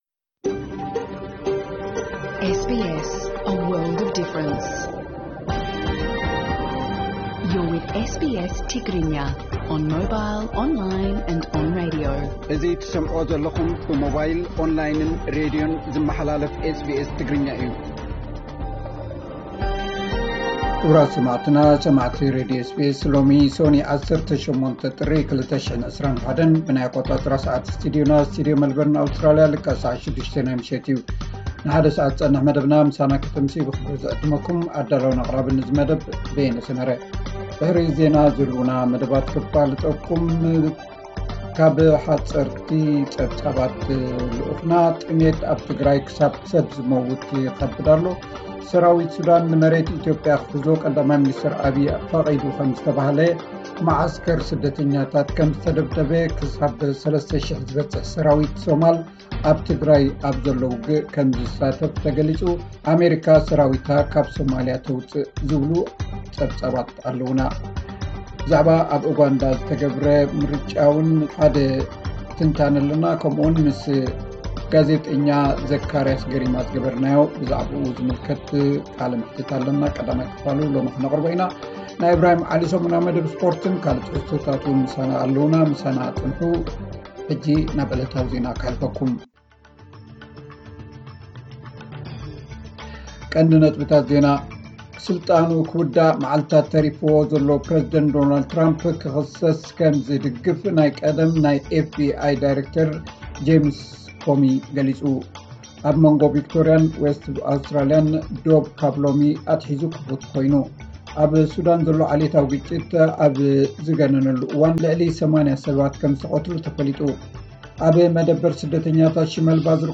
ዕለታዊ ዜና 18 ጥሪ 2021 SBS ትግርኛ